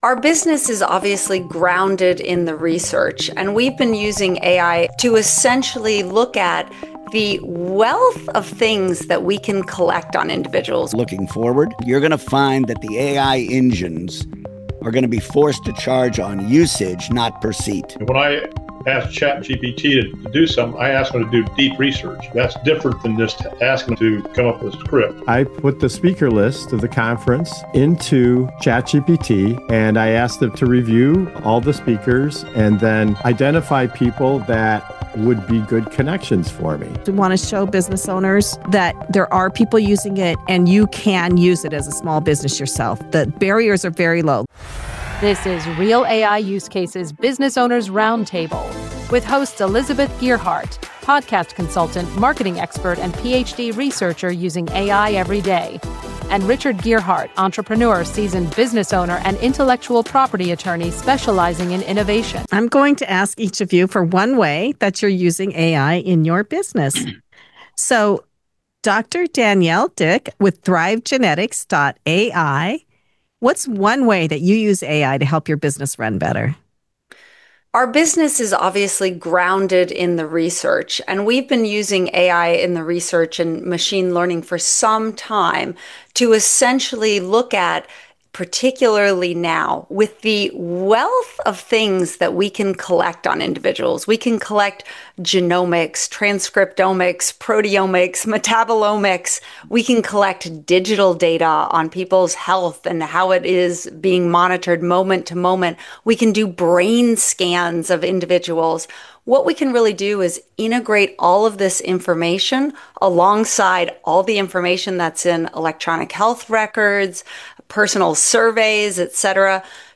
Business Owners Roundtable AI in Action: Small Business Owners Share What's Actually Working